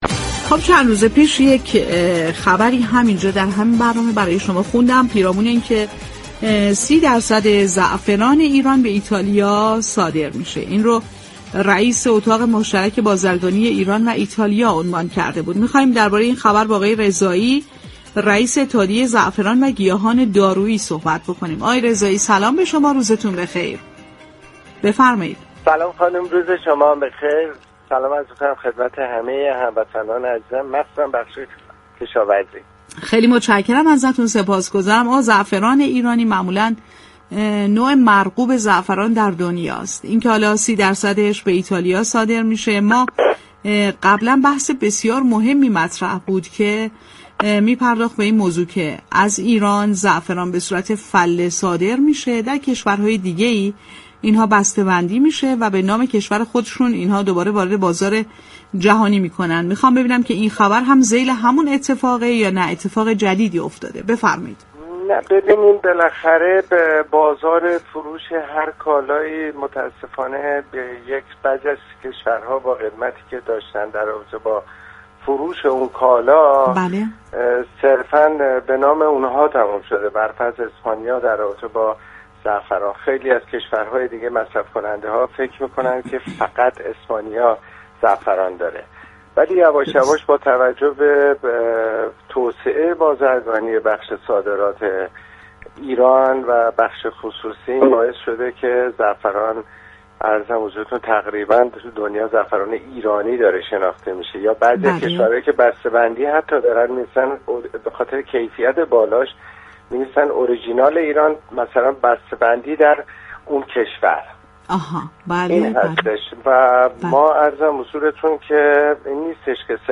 گفت‌وگو با رادیو تهران